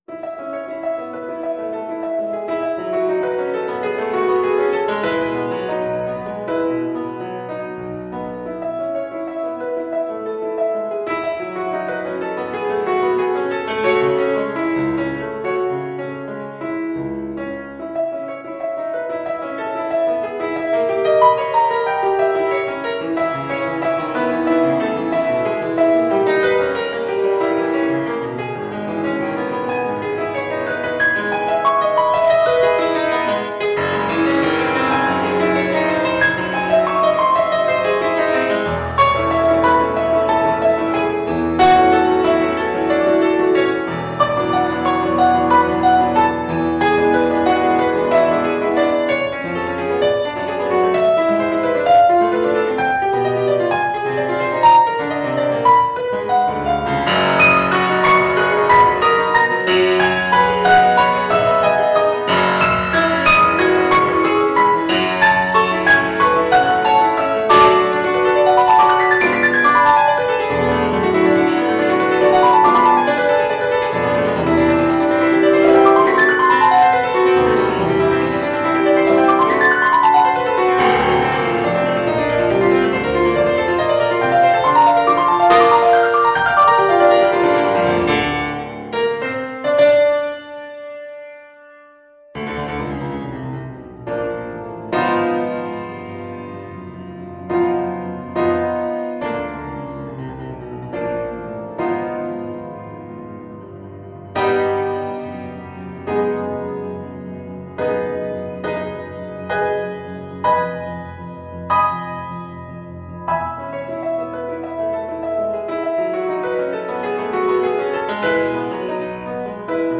Piano version
Style: Classical